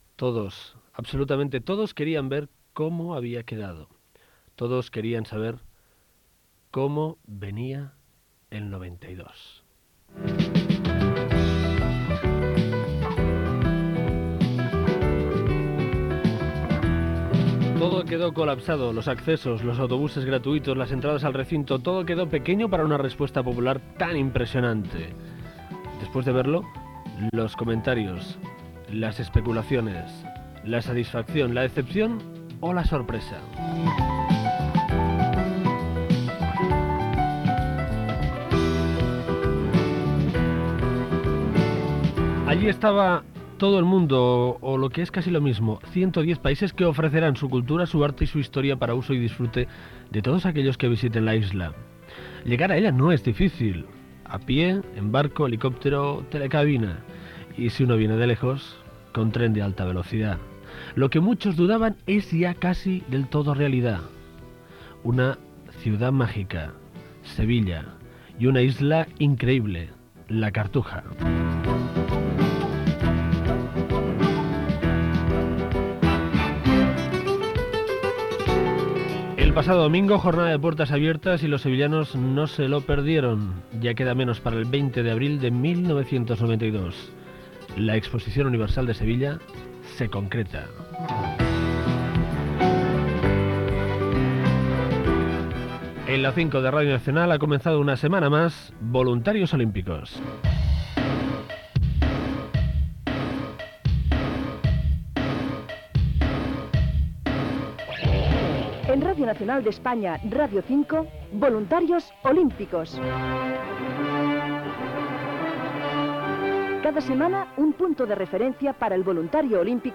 Emissora Radio 5 Barcelona Cadena RNE
Portes obertes a la seu on es celebrarà l'Exposició Universal de Sevilla, indicatiu del programa, hora, objectiu del programa, els Jocs Paralímpics Barcelona 1992, indicatiu, resum setmanal d'actualitat olímpica (amb declaracions de Juan Antonio Samaranch), indicatiu Gènere radiofònic Divulgació